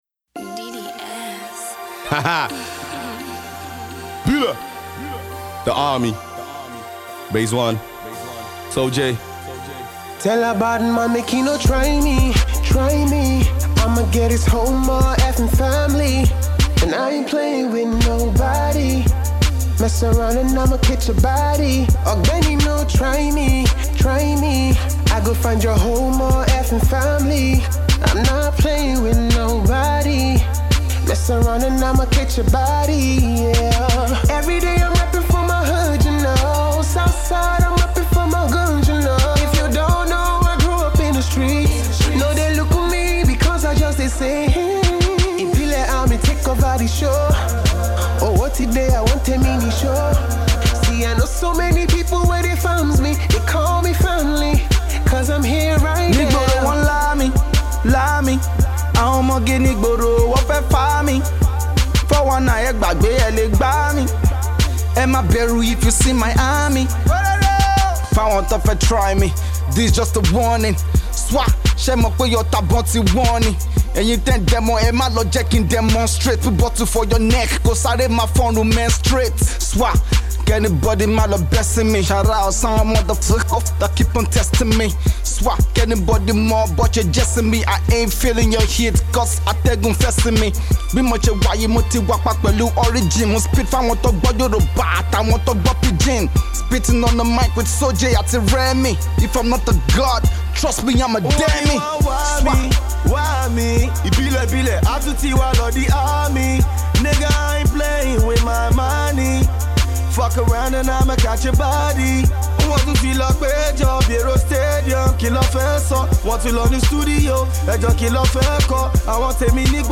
Its a Cover